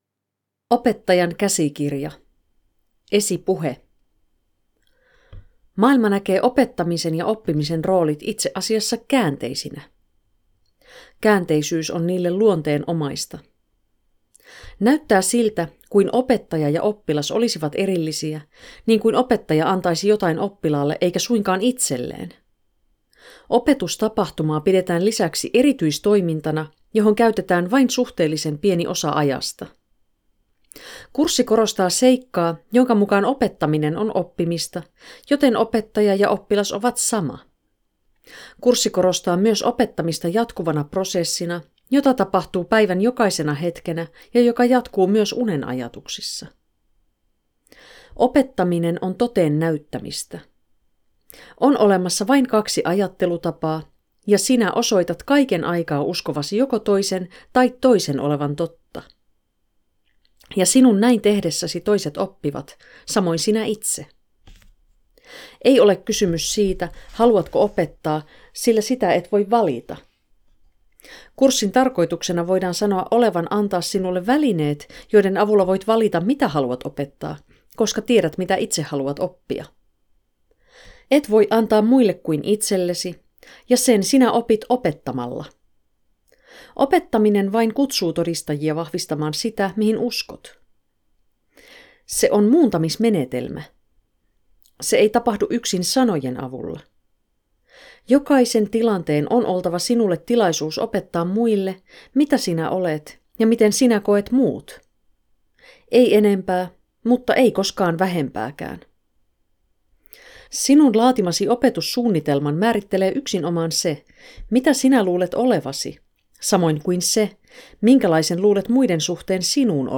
Announcing the Finnish audiobook!
recorded in Finland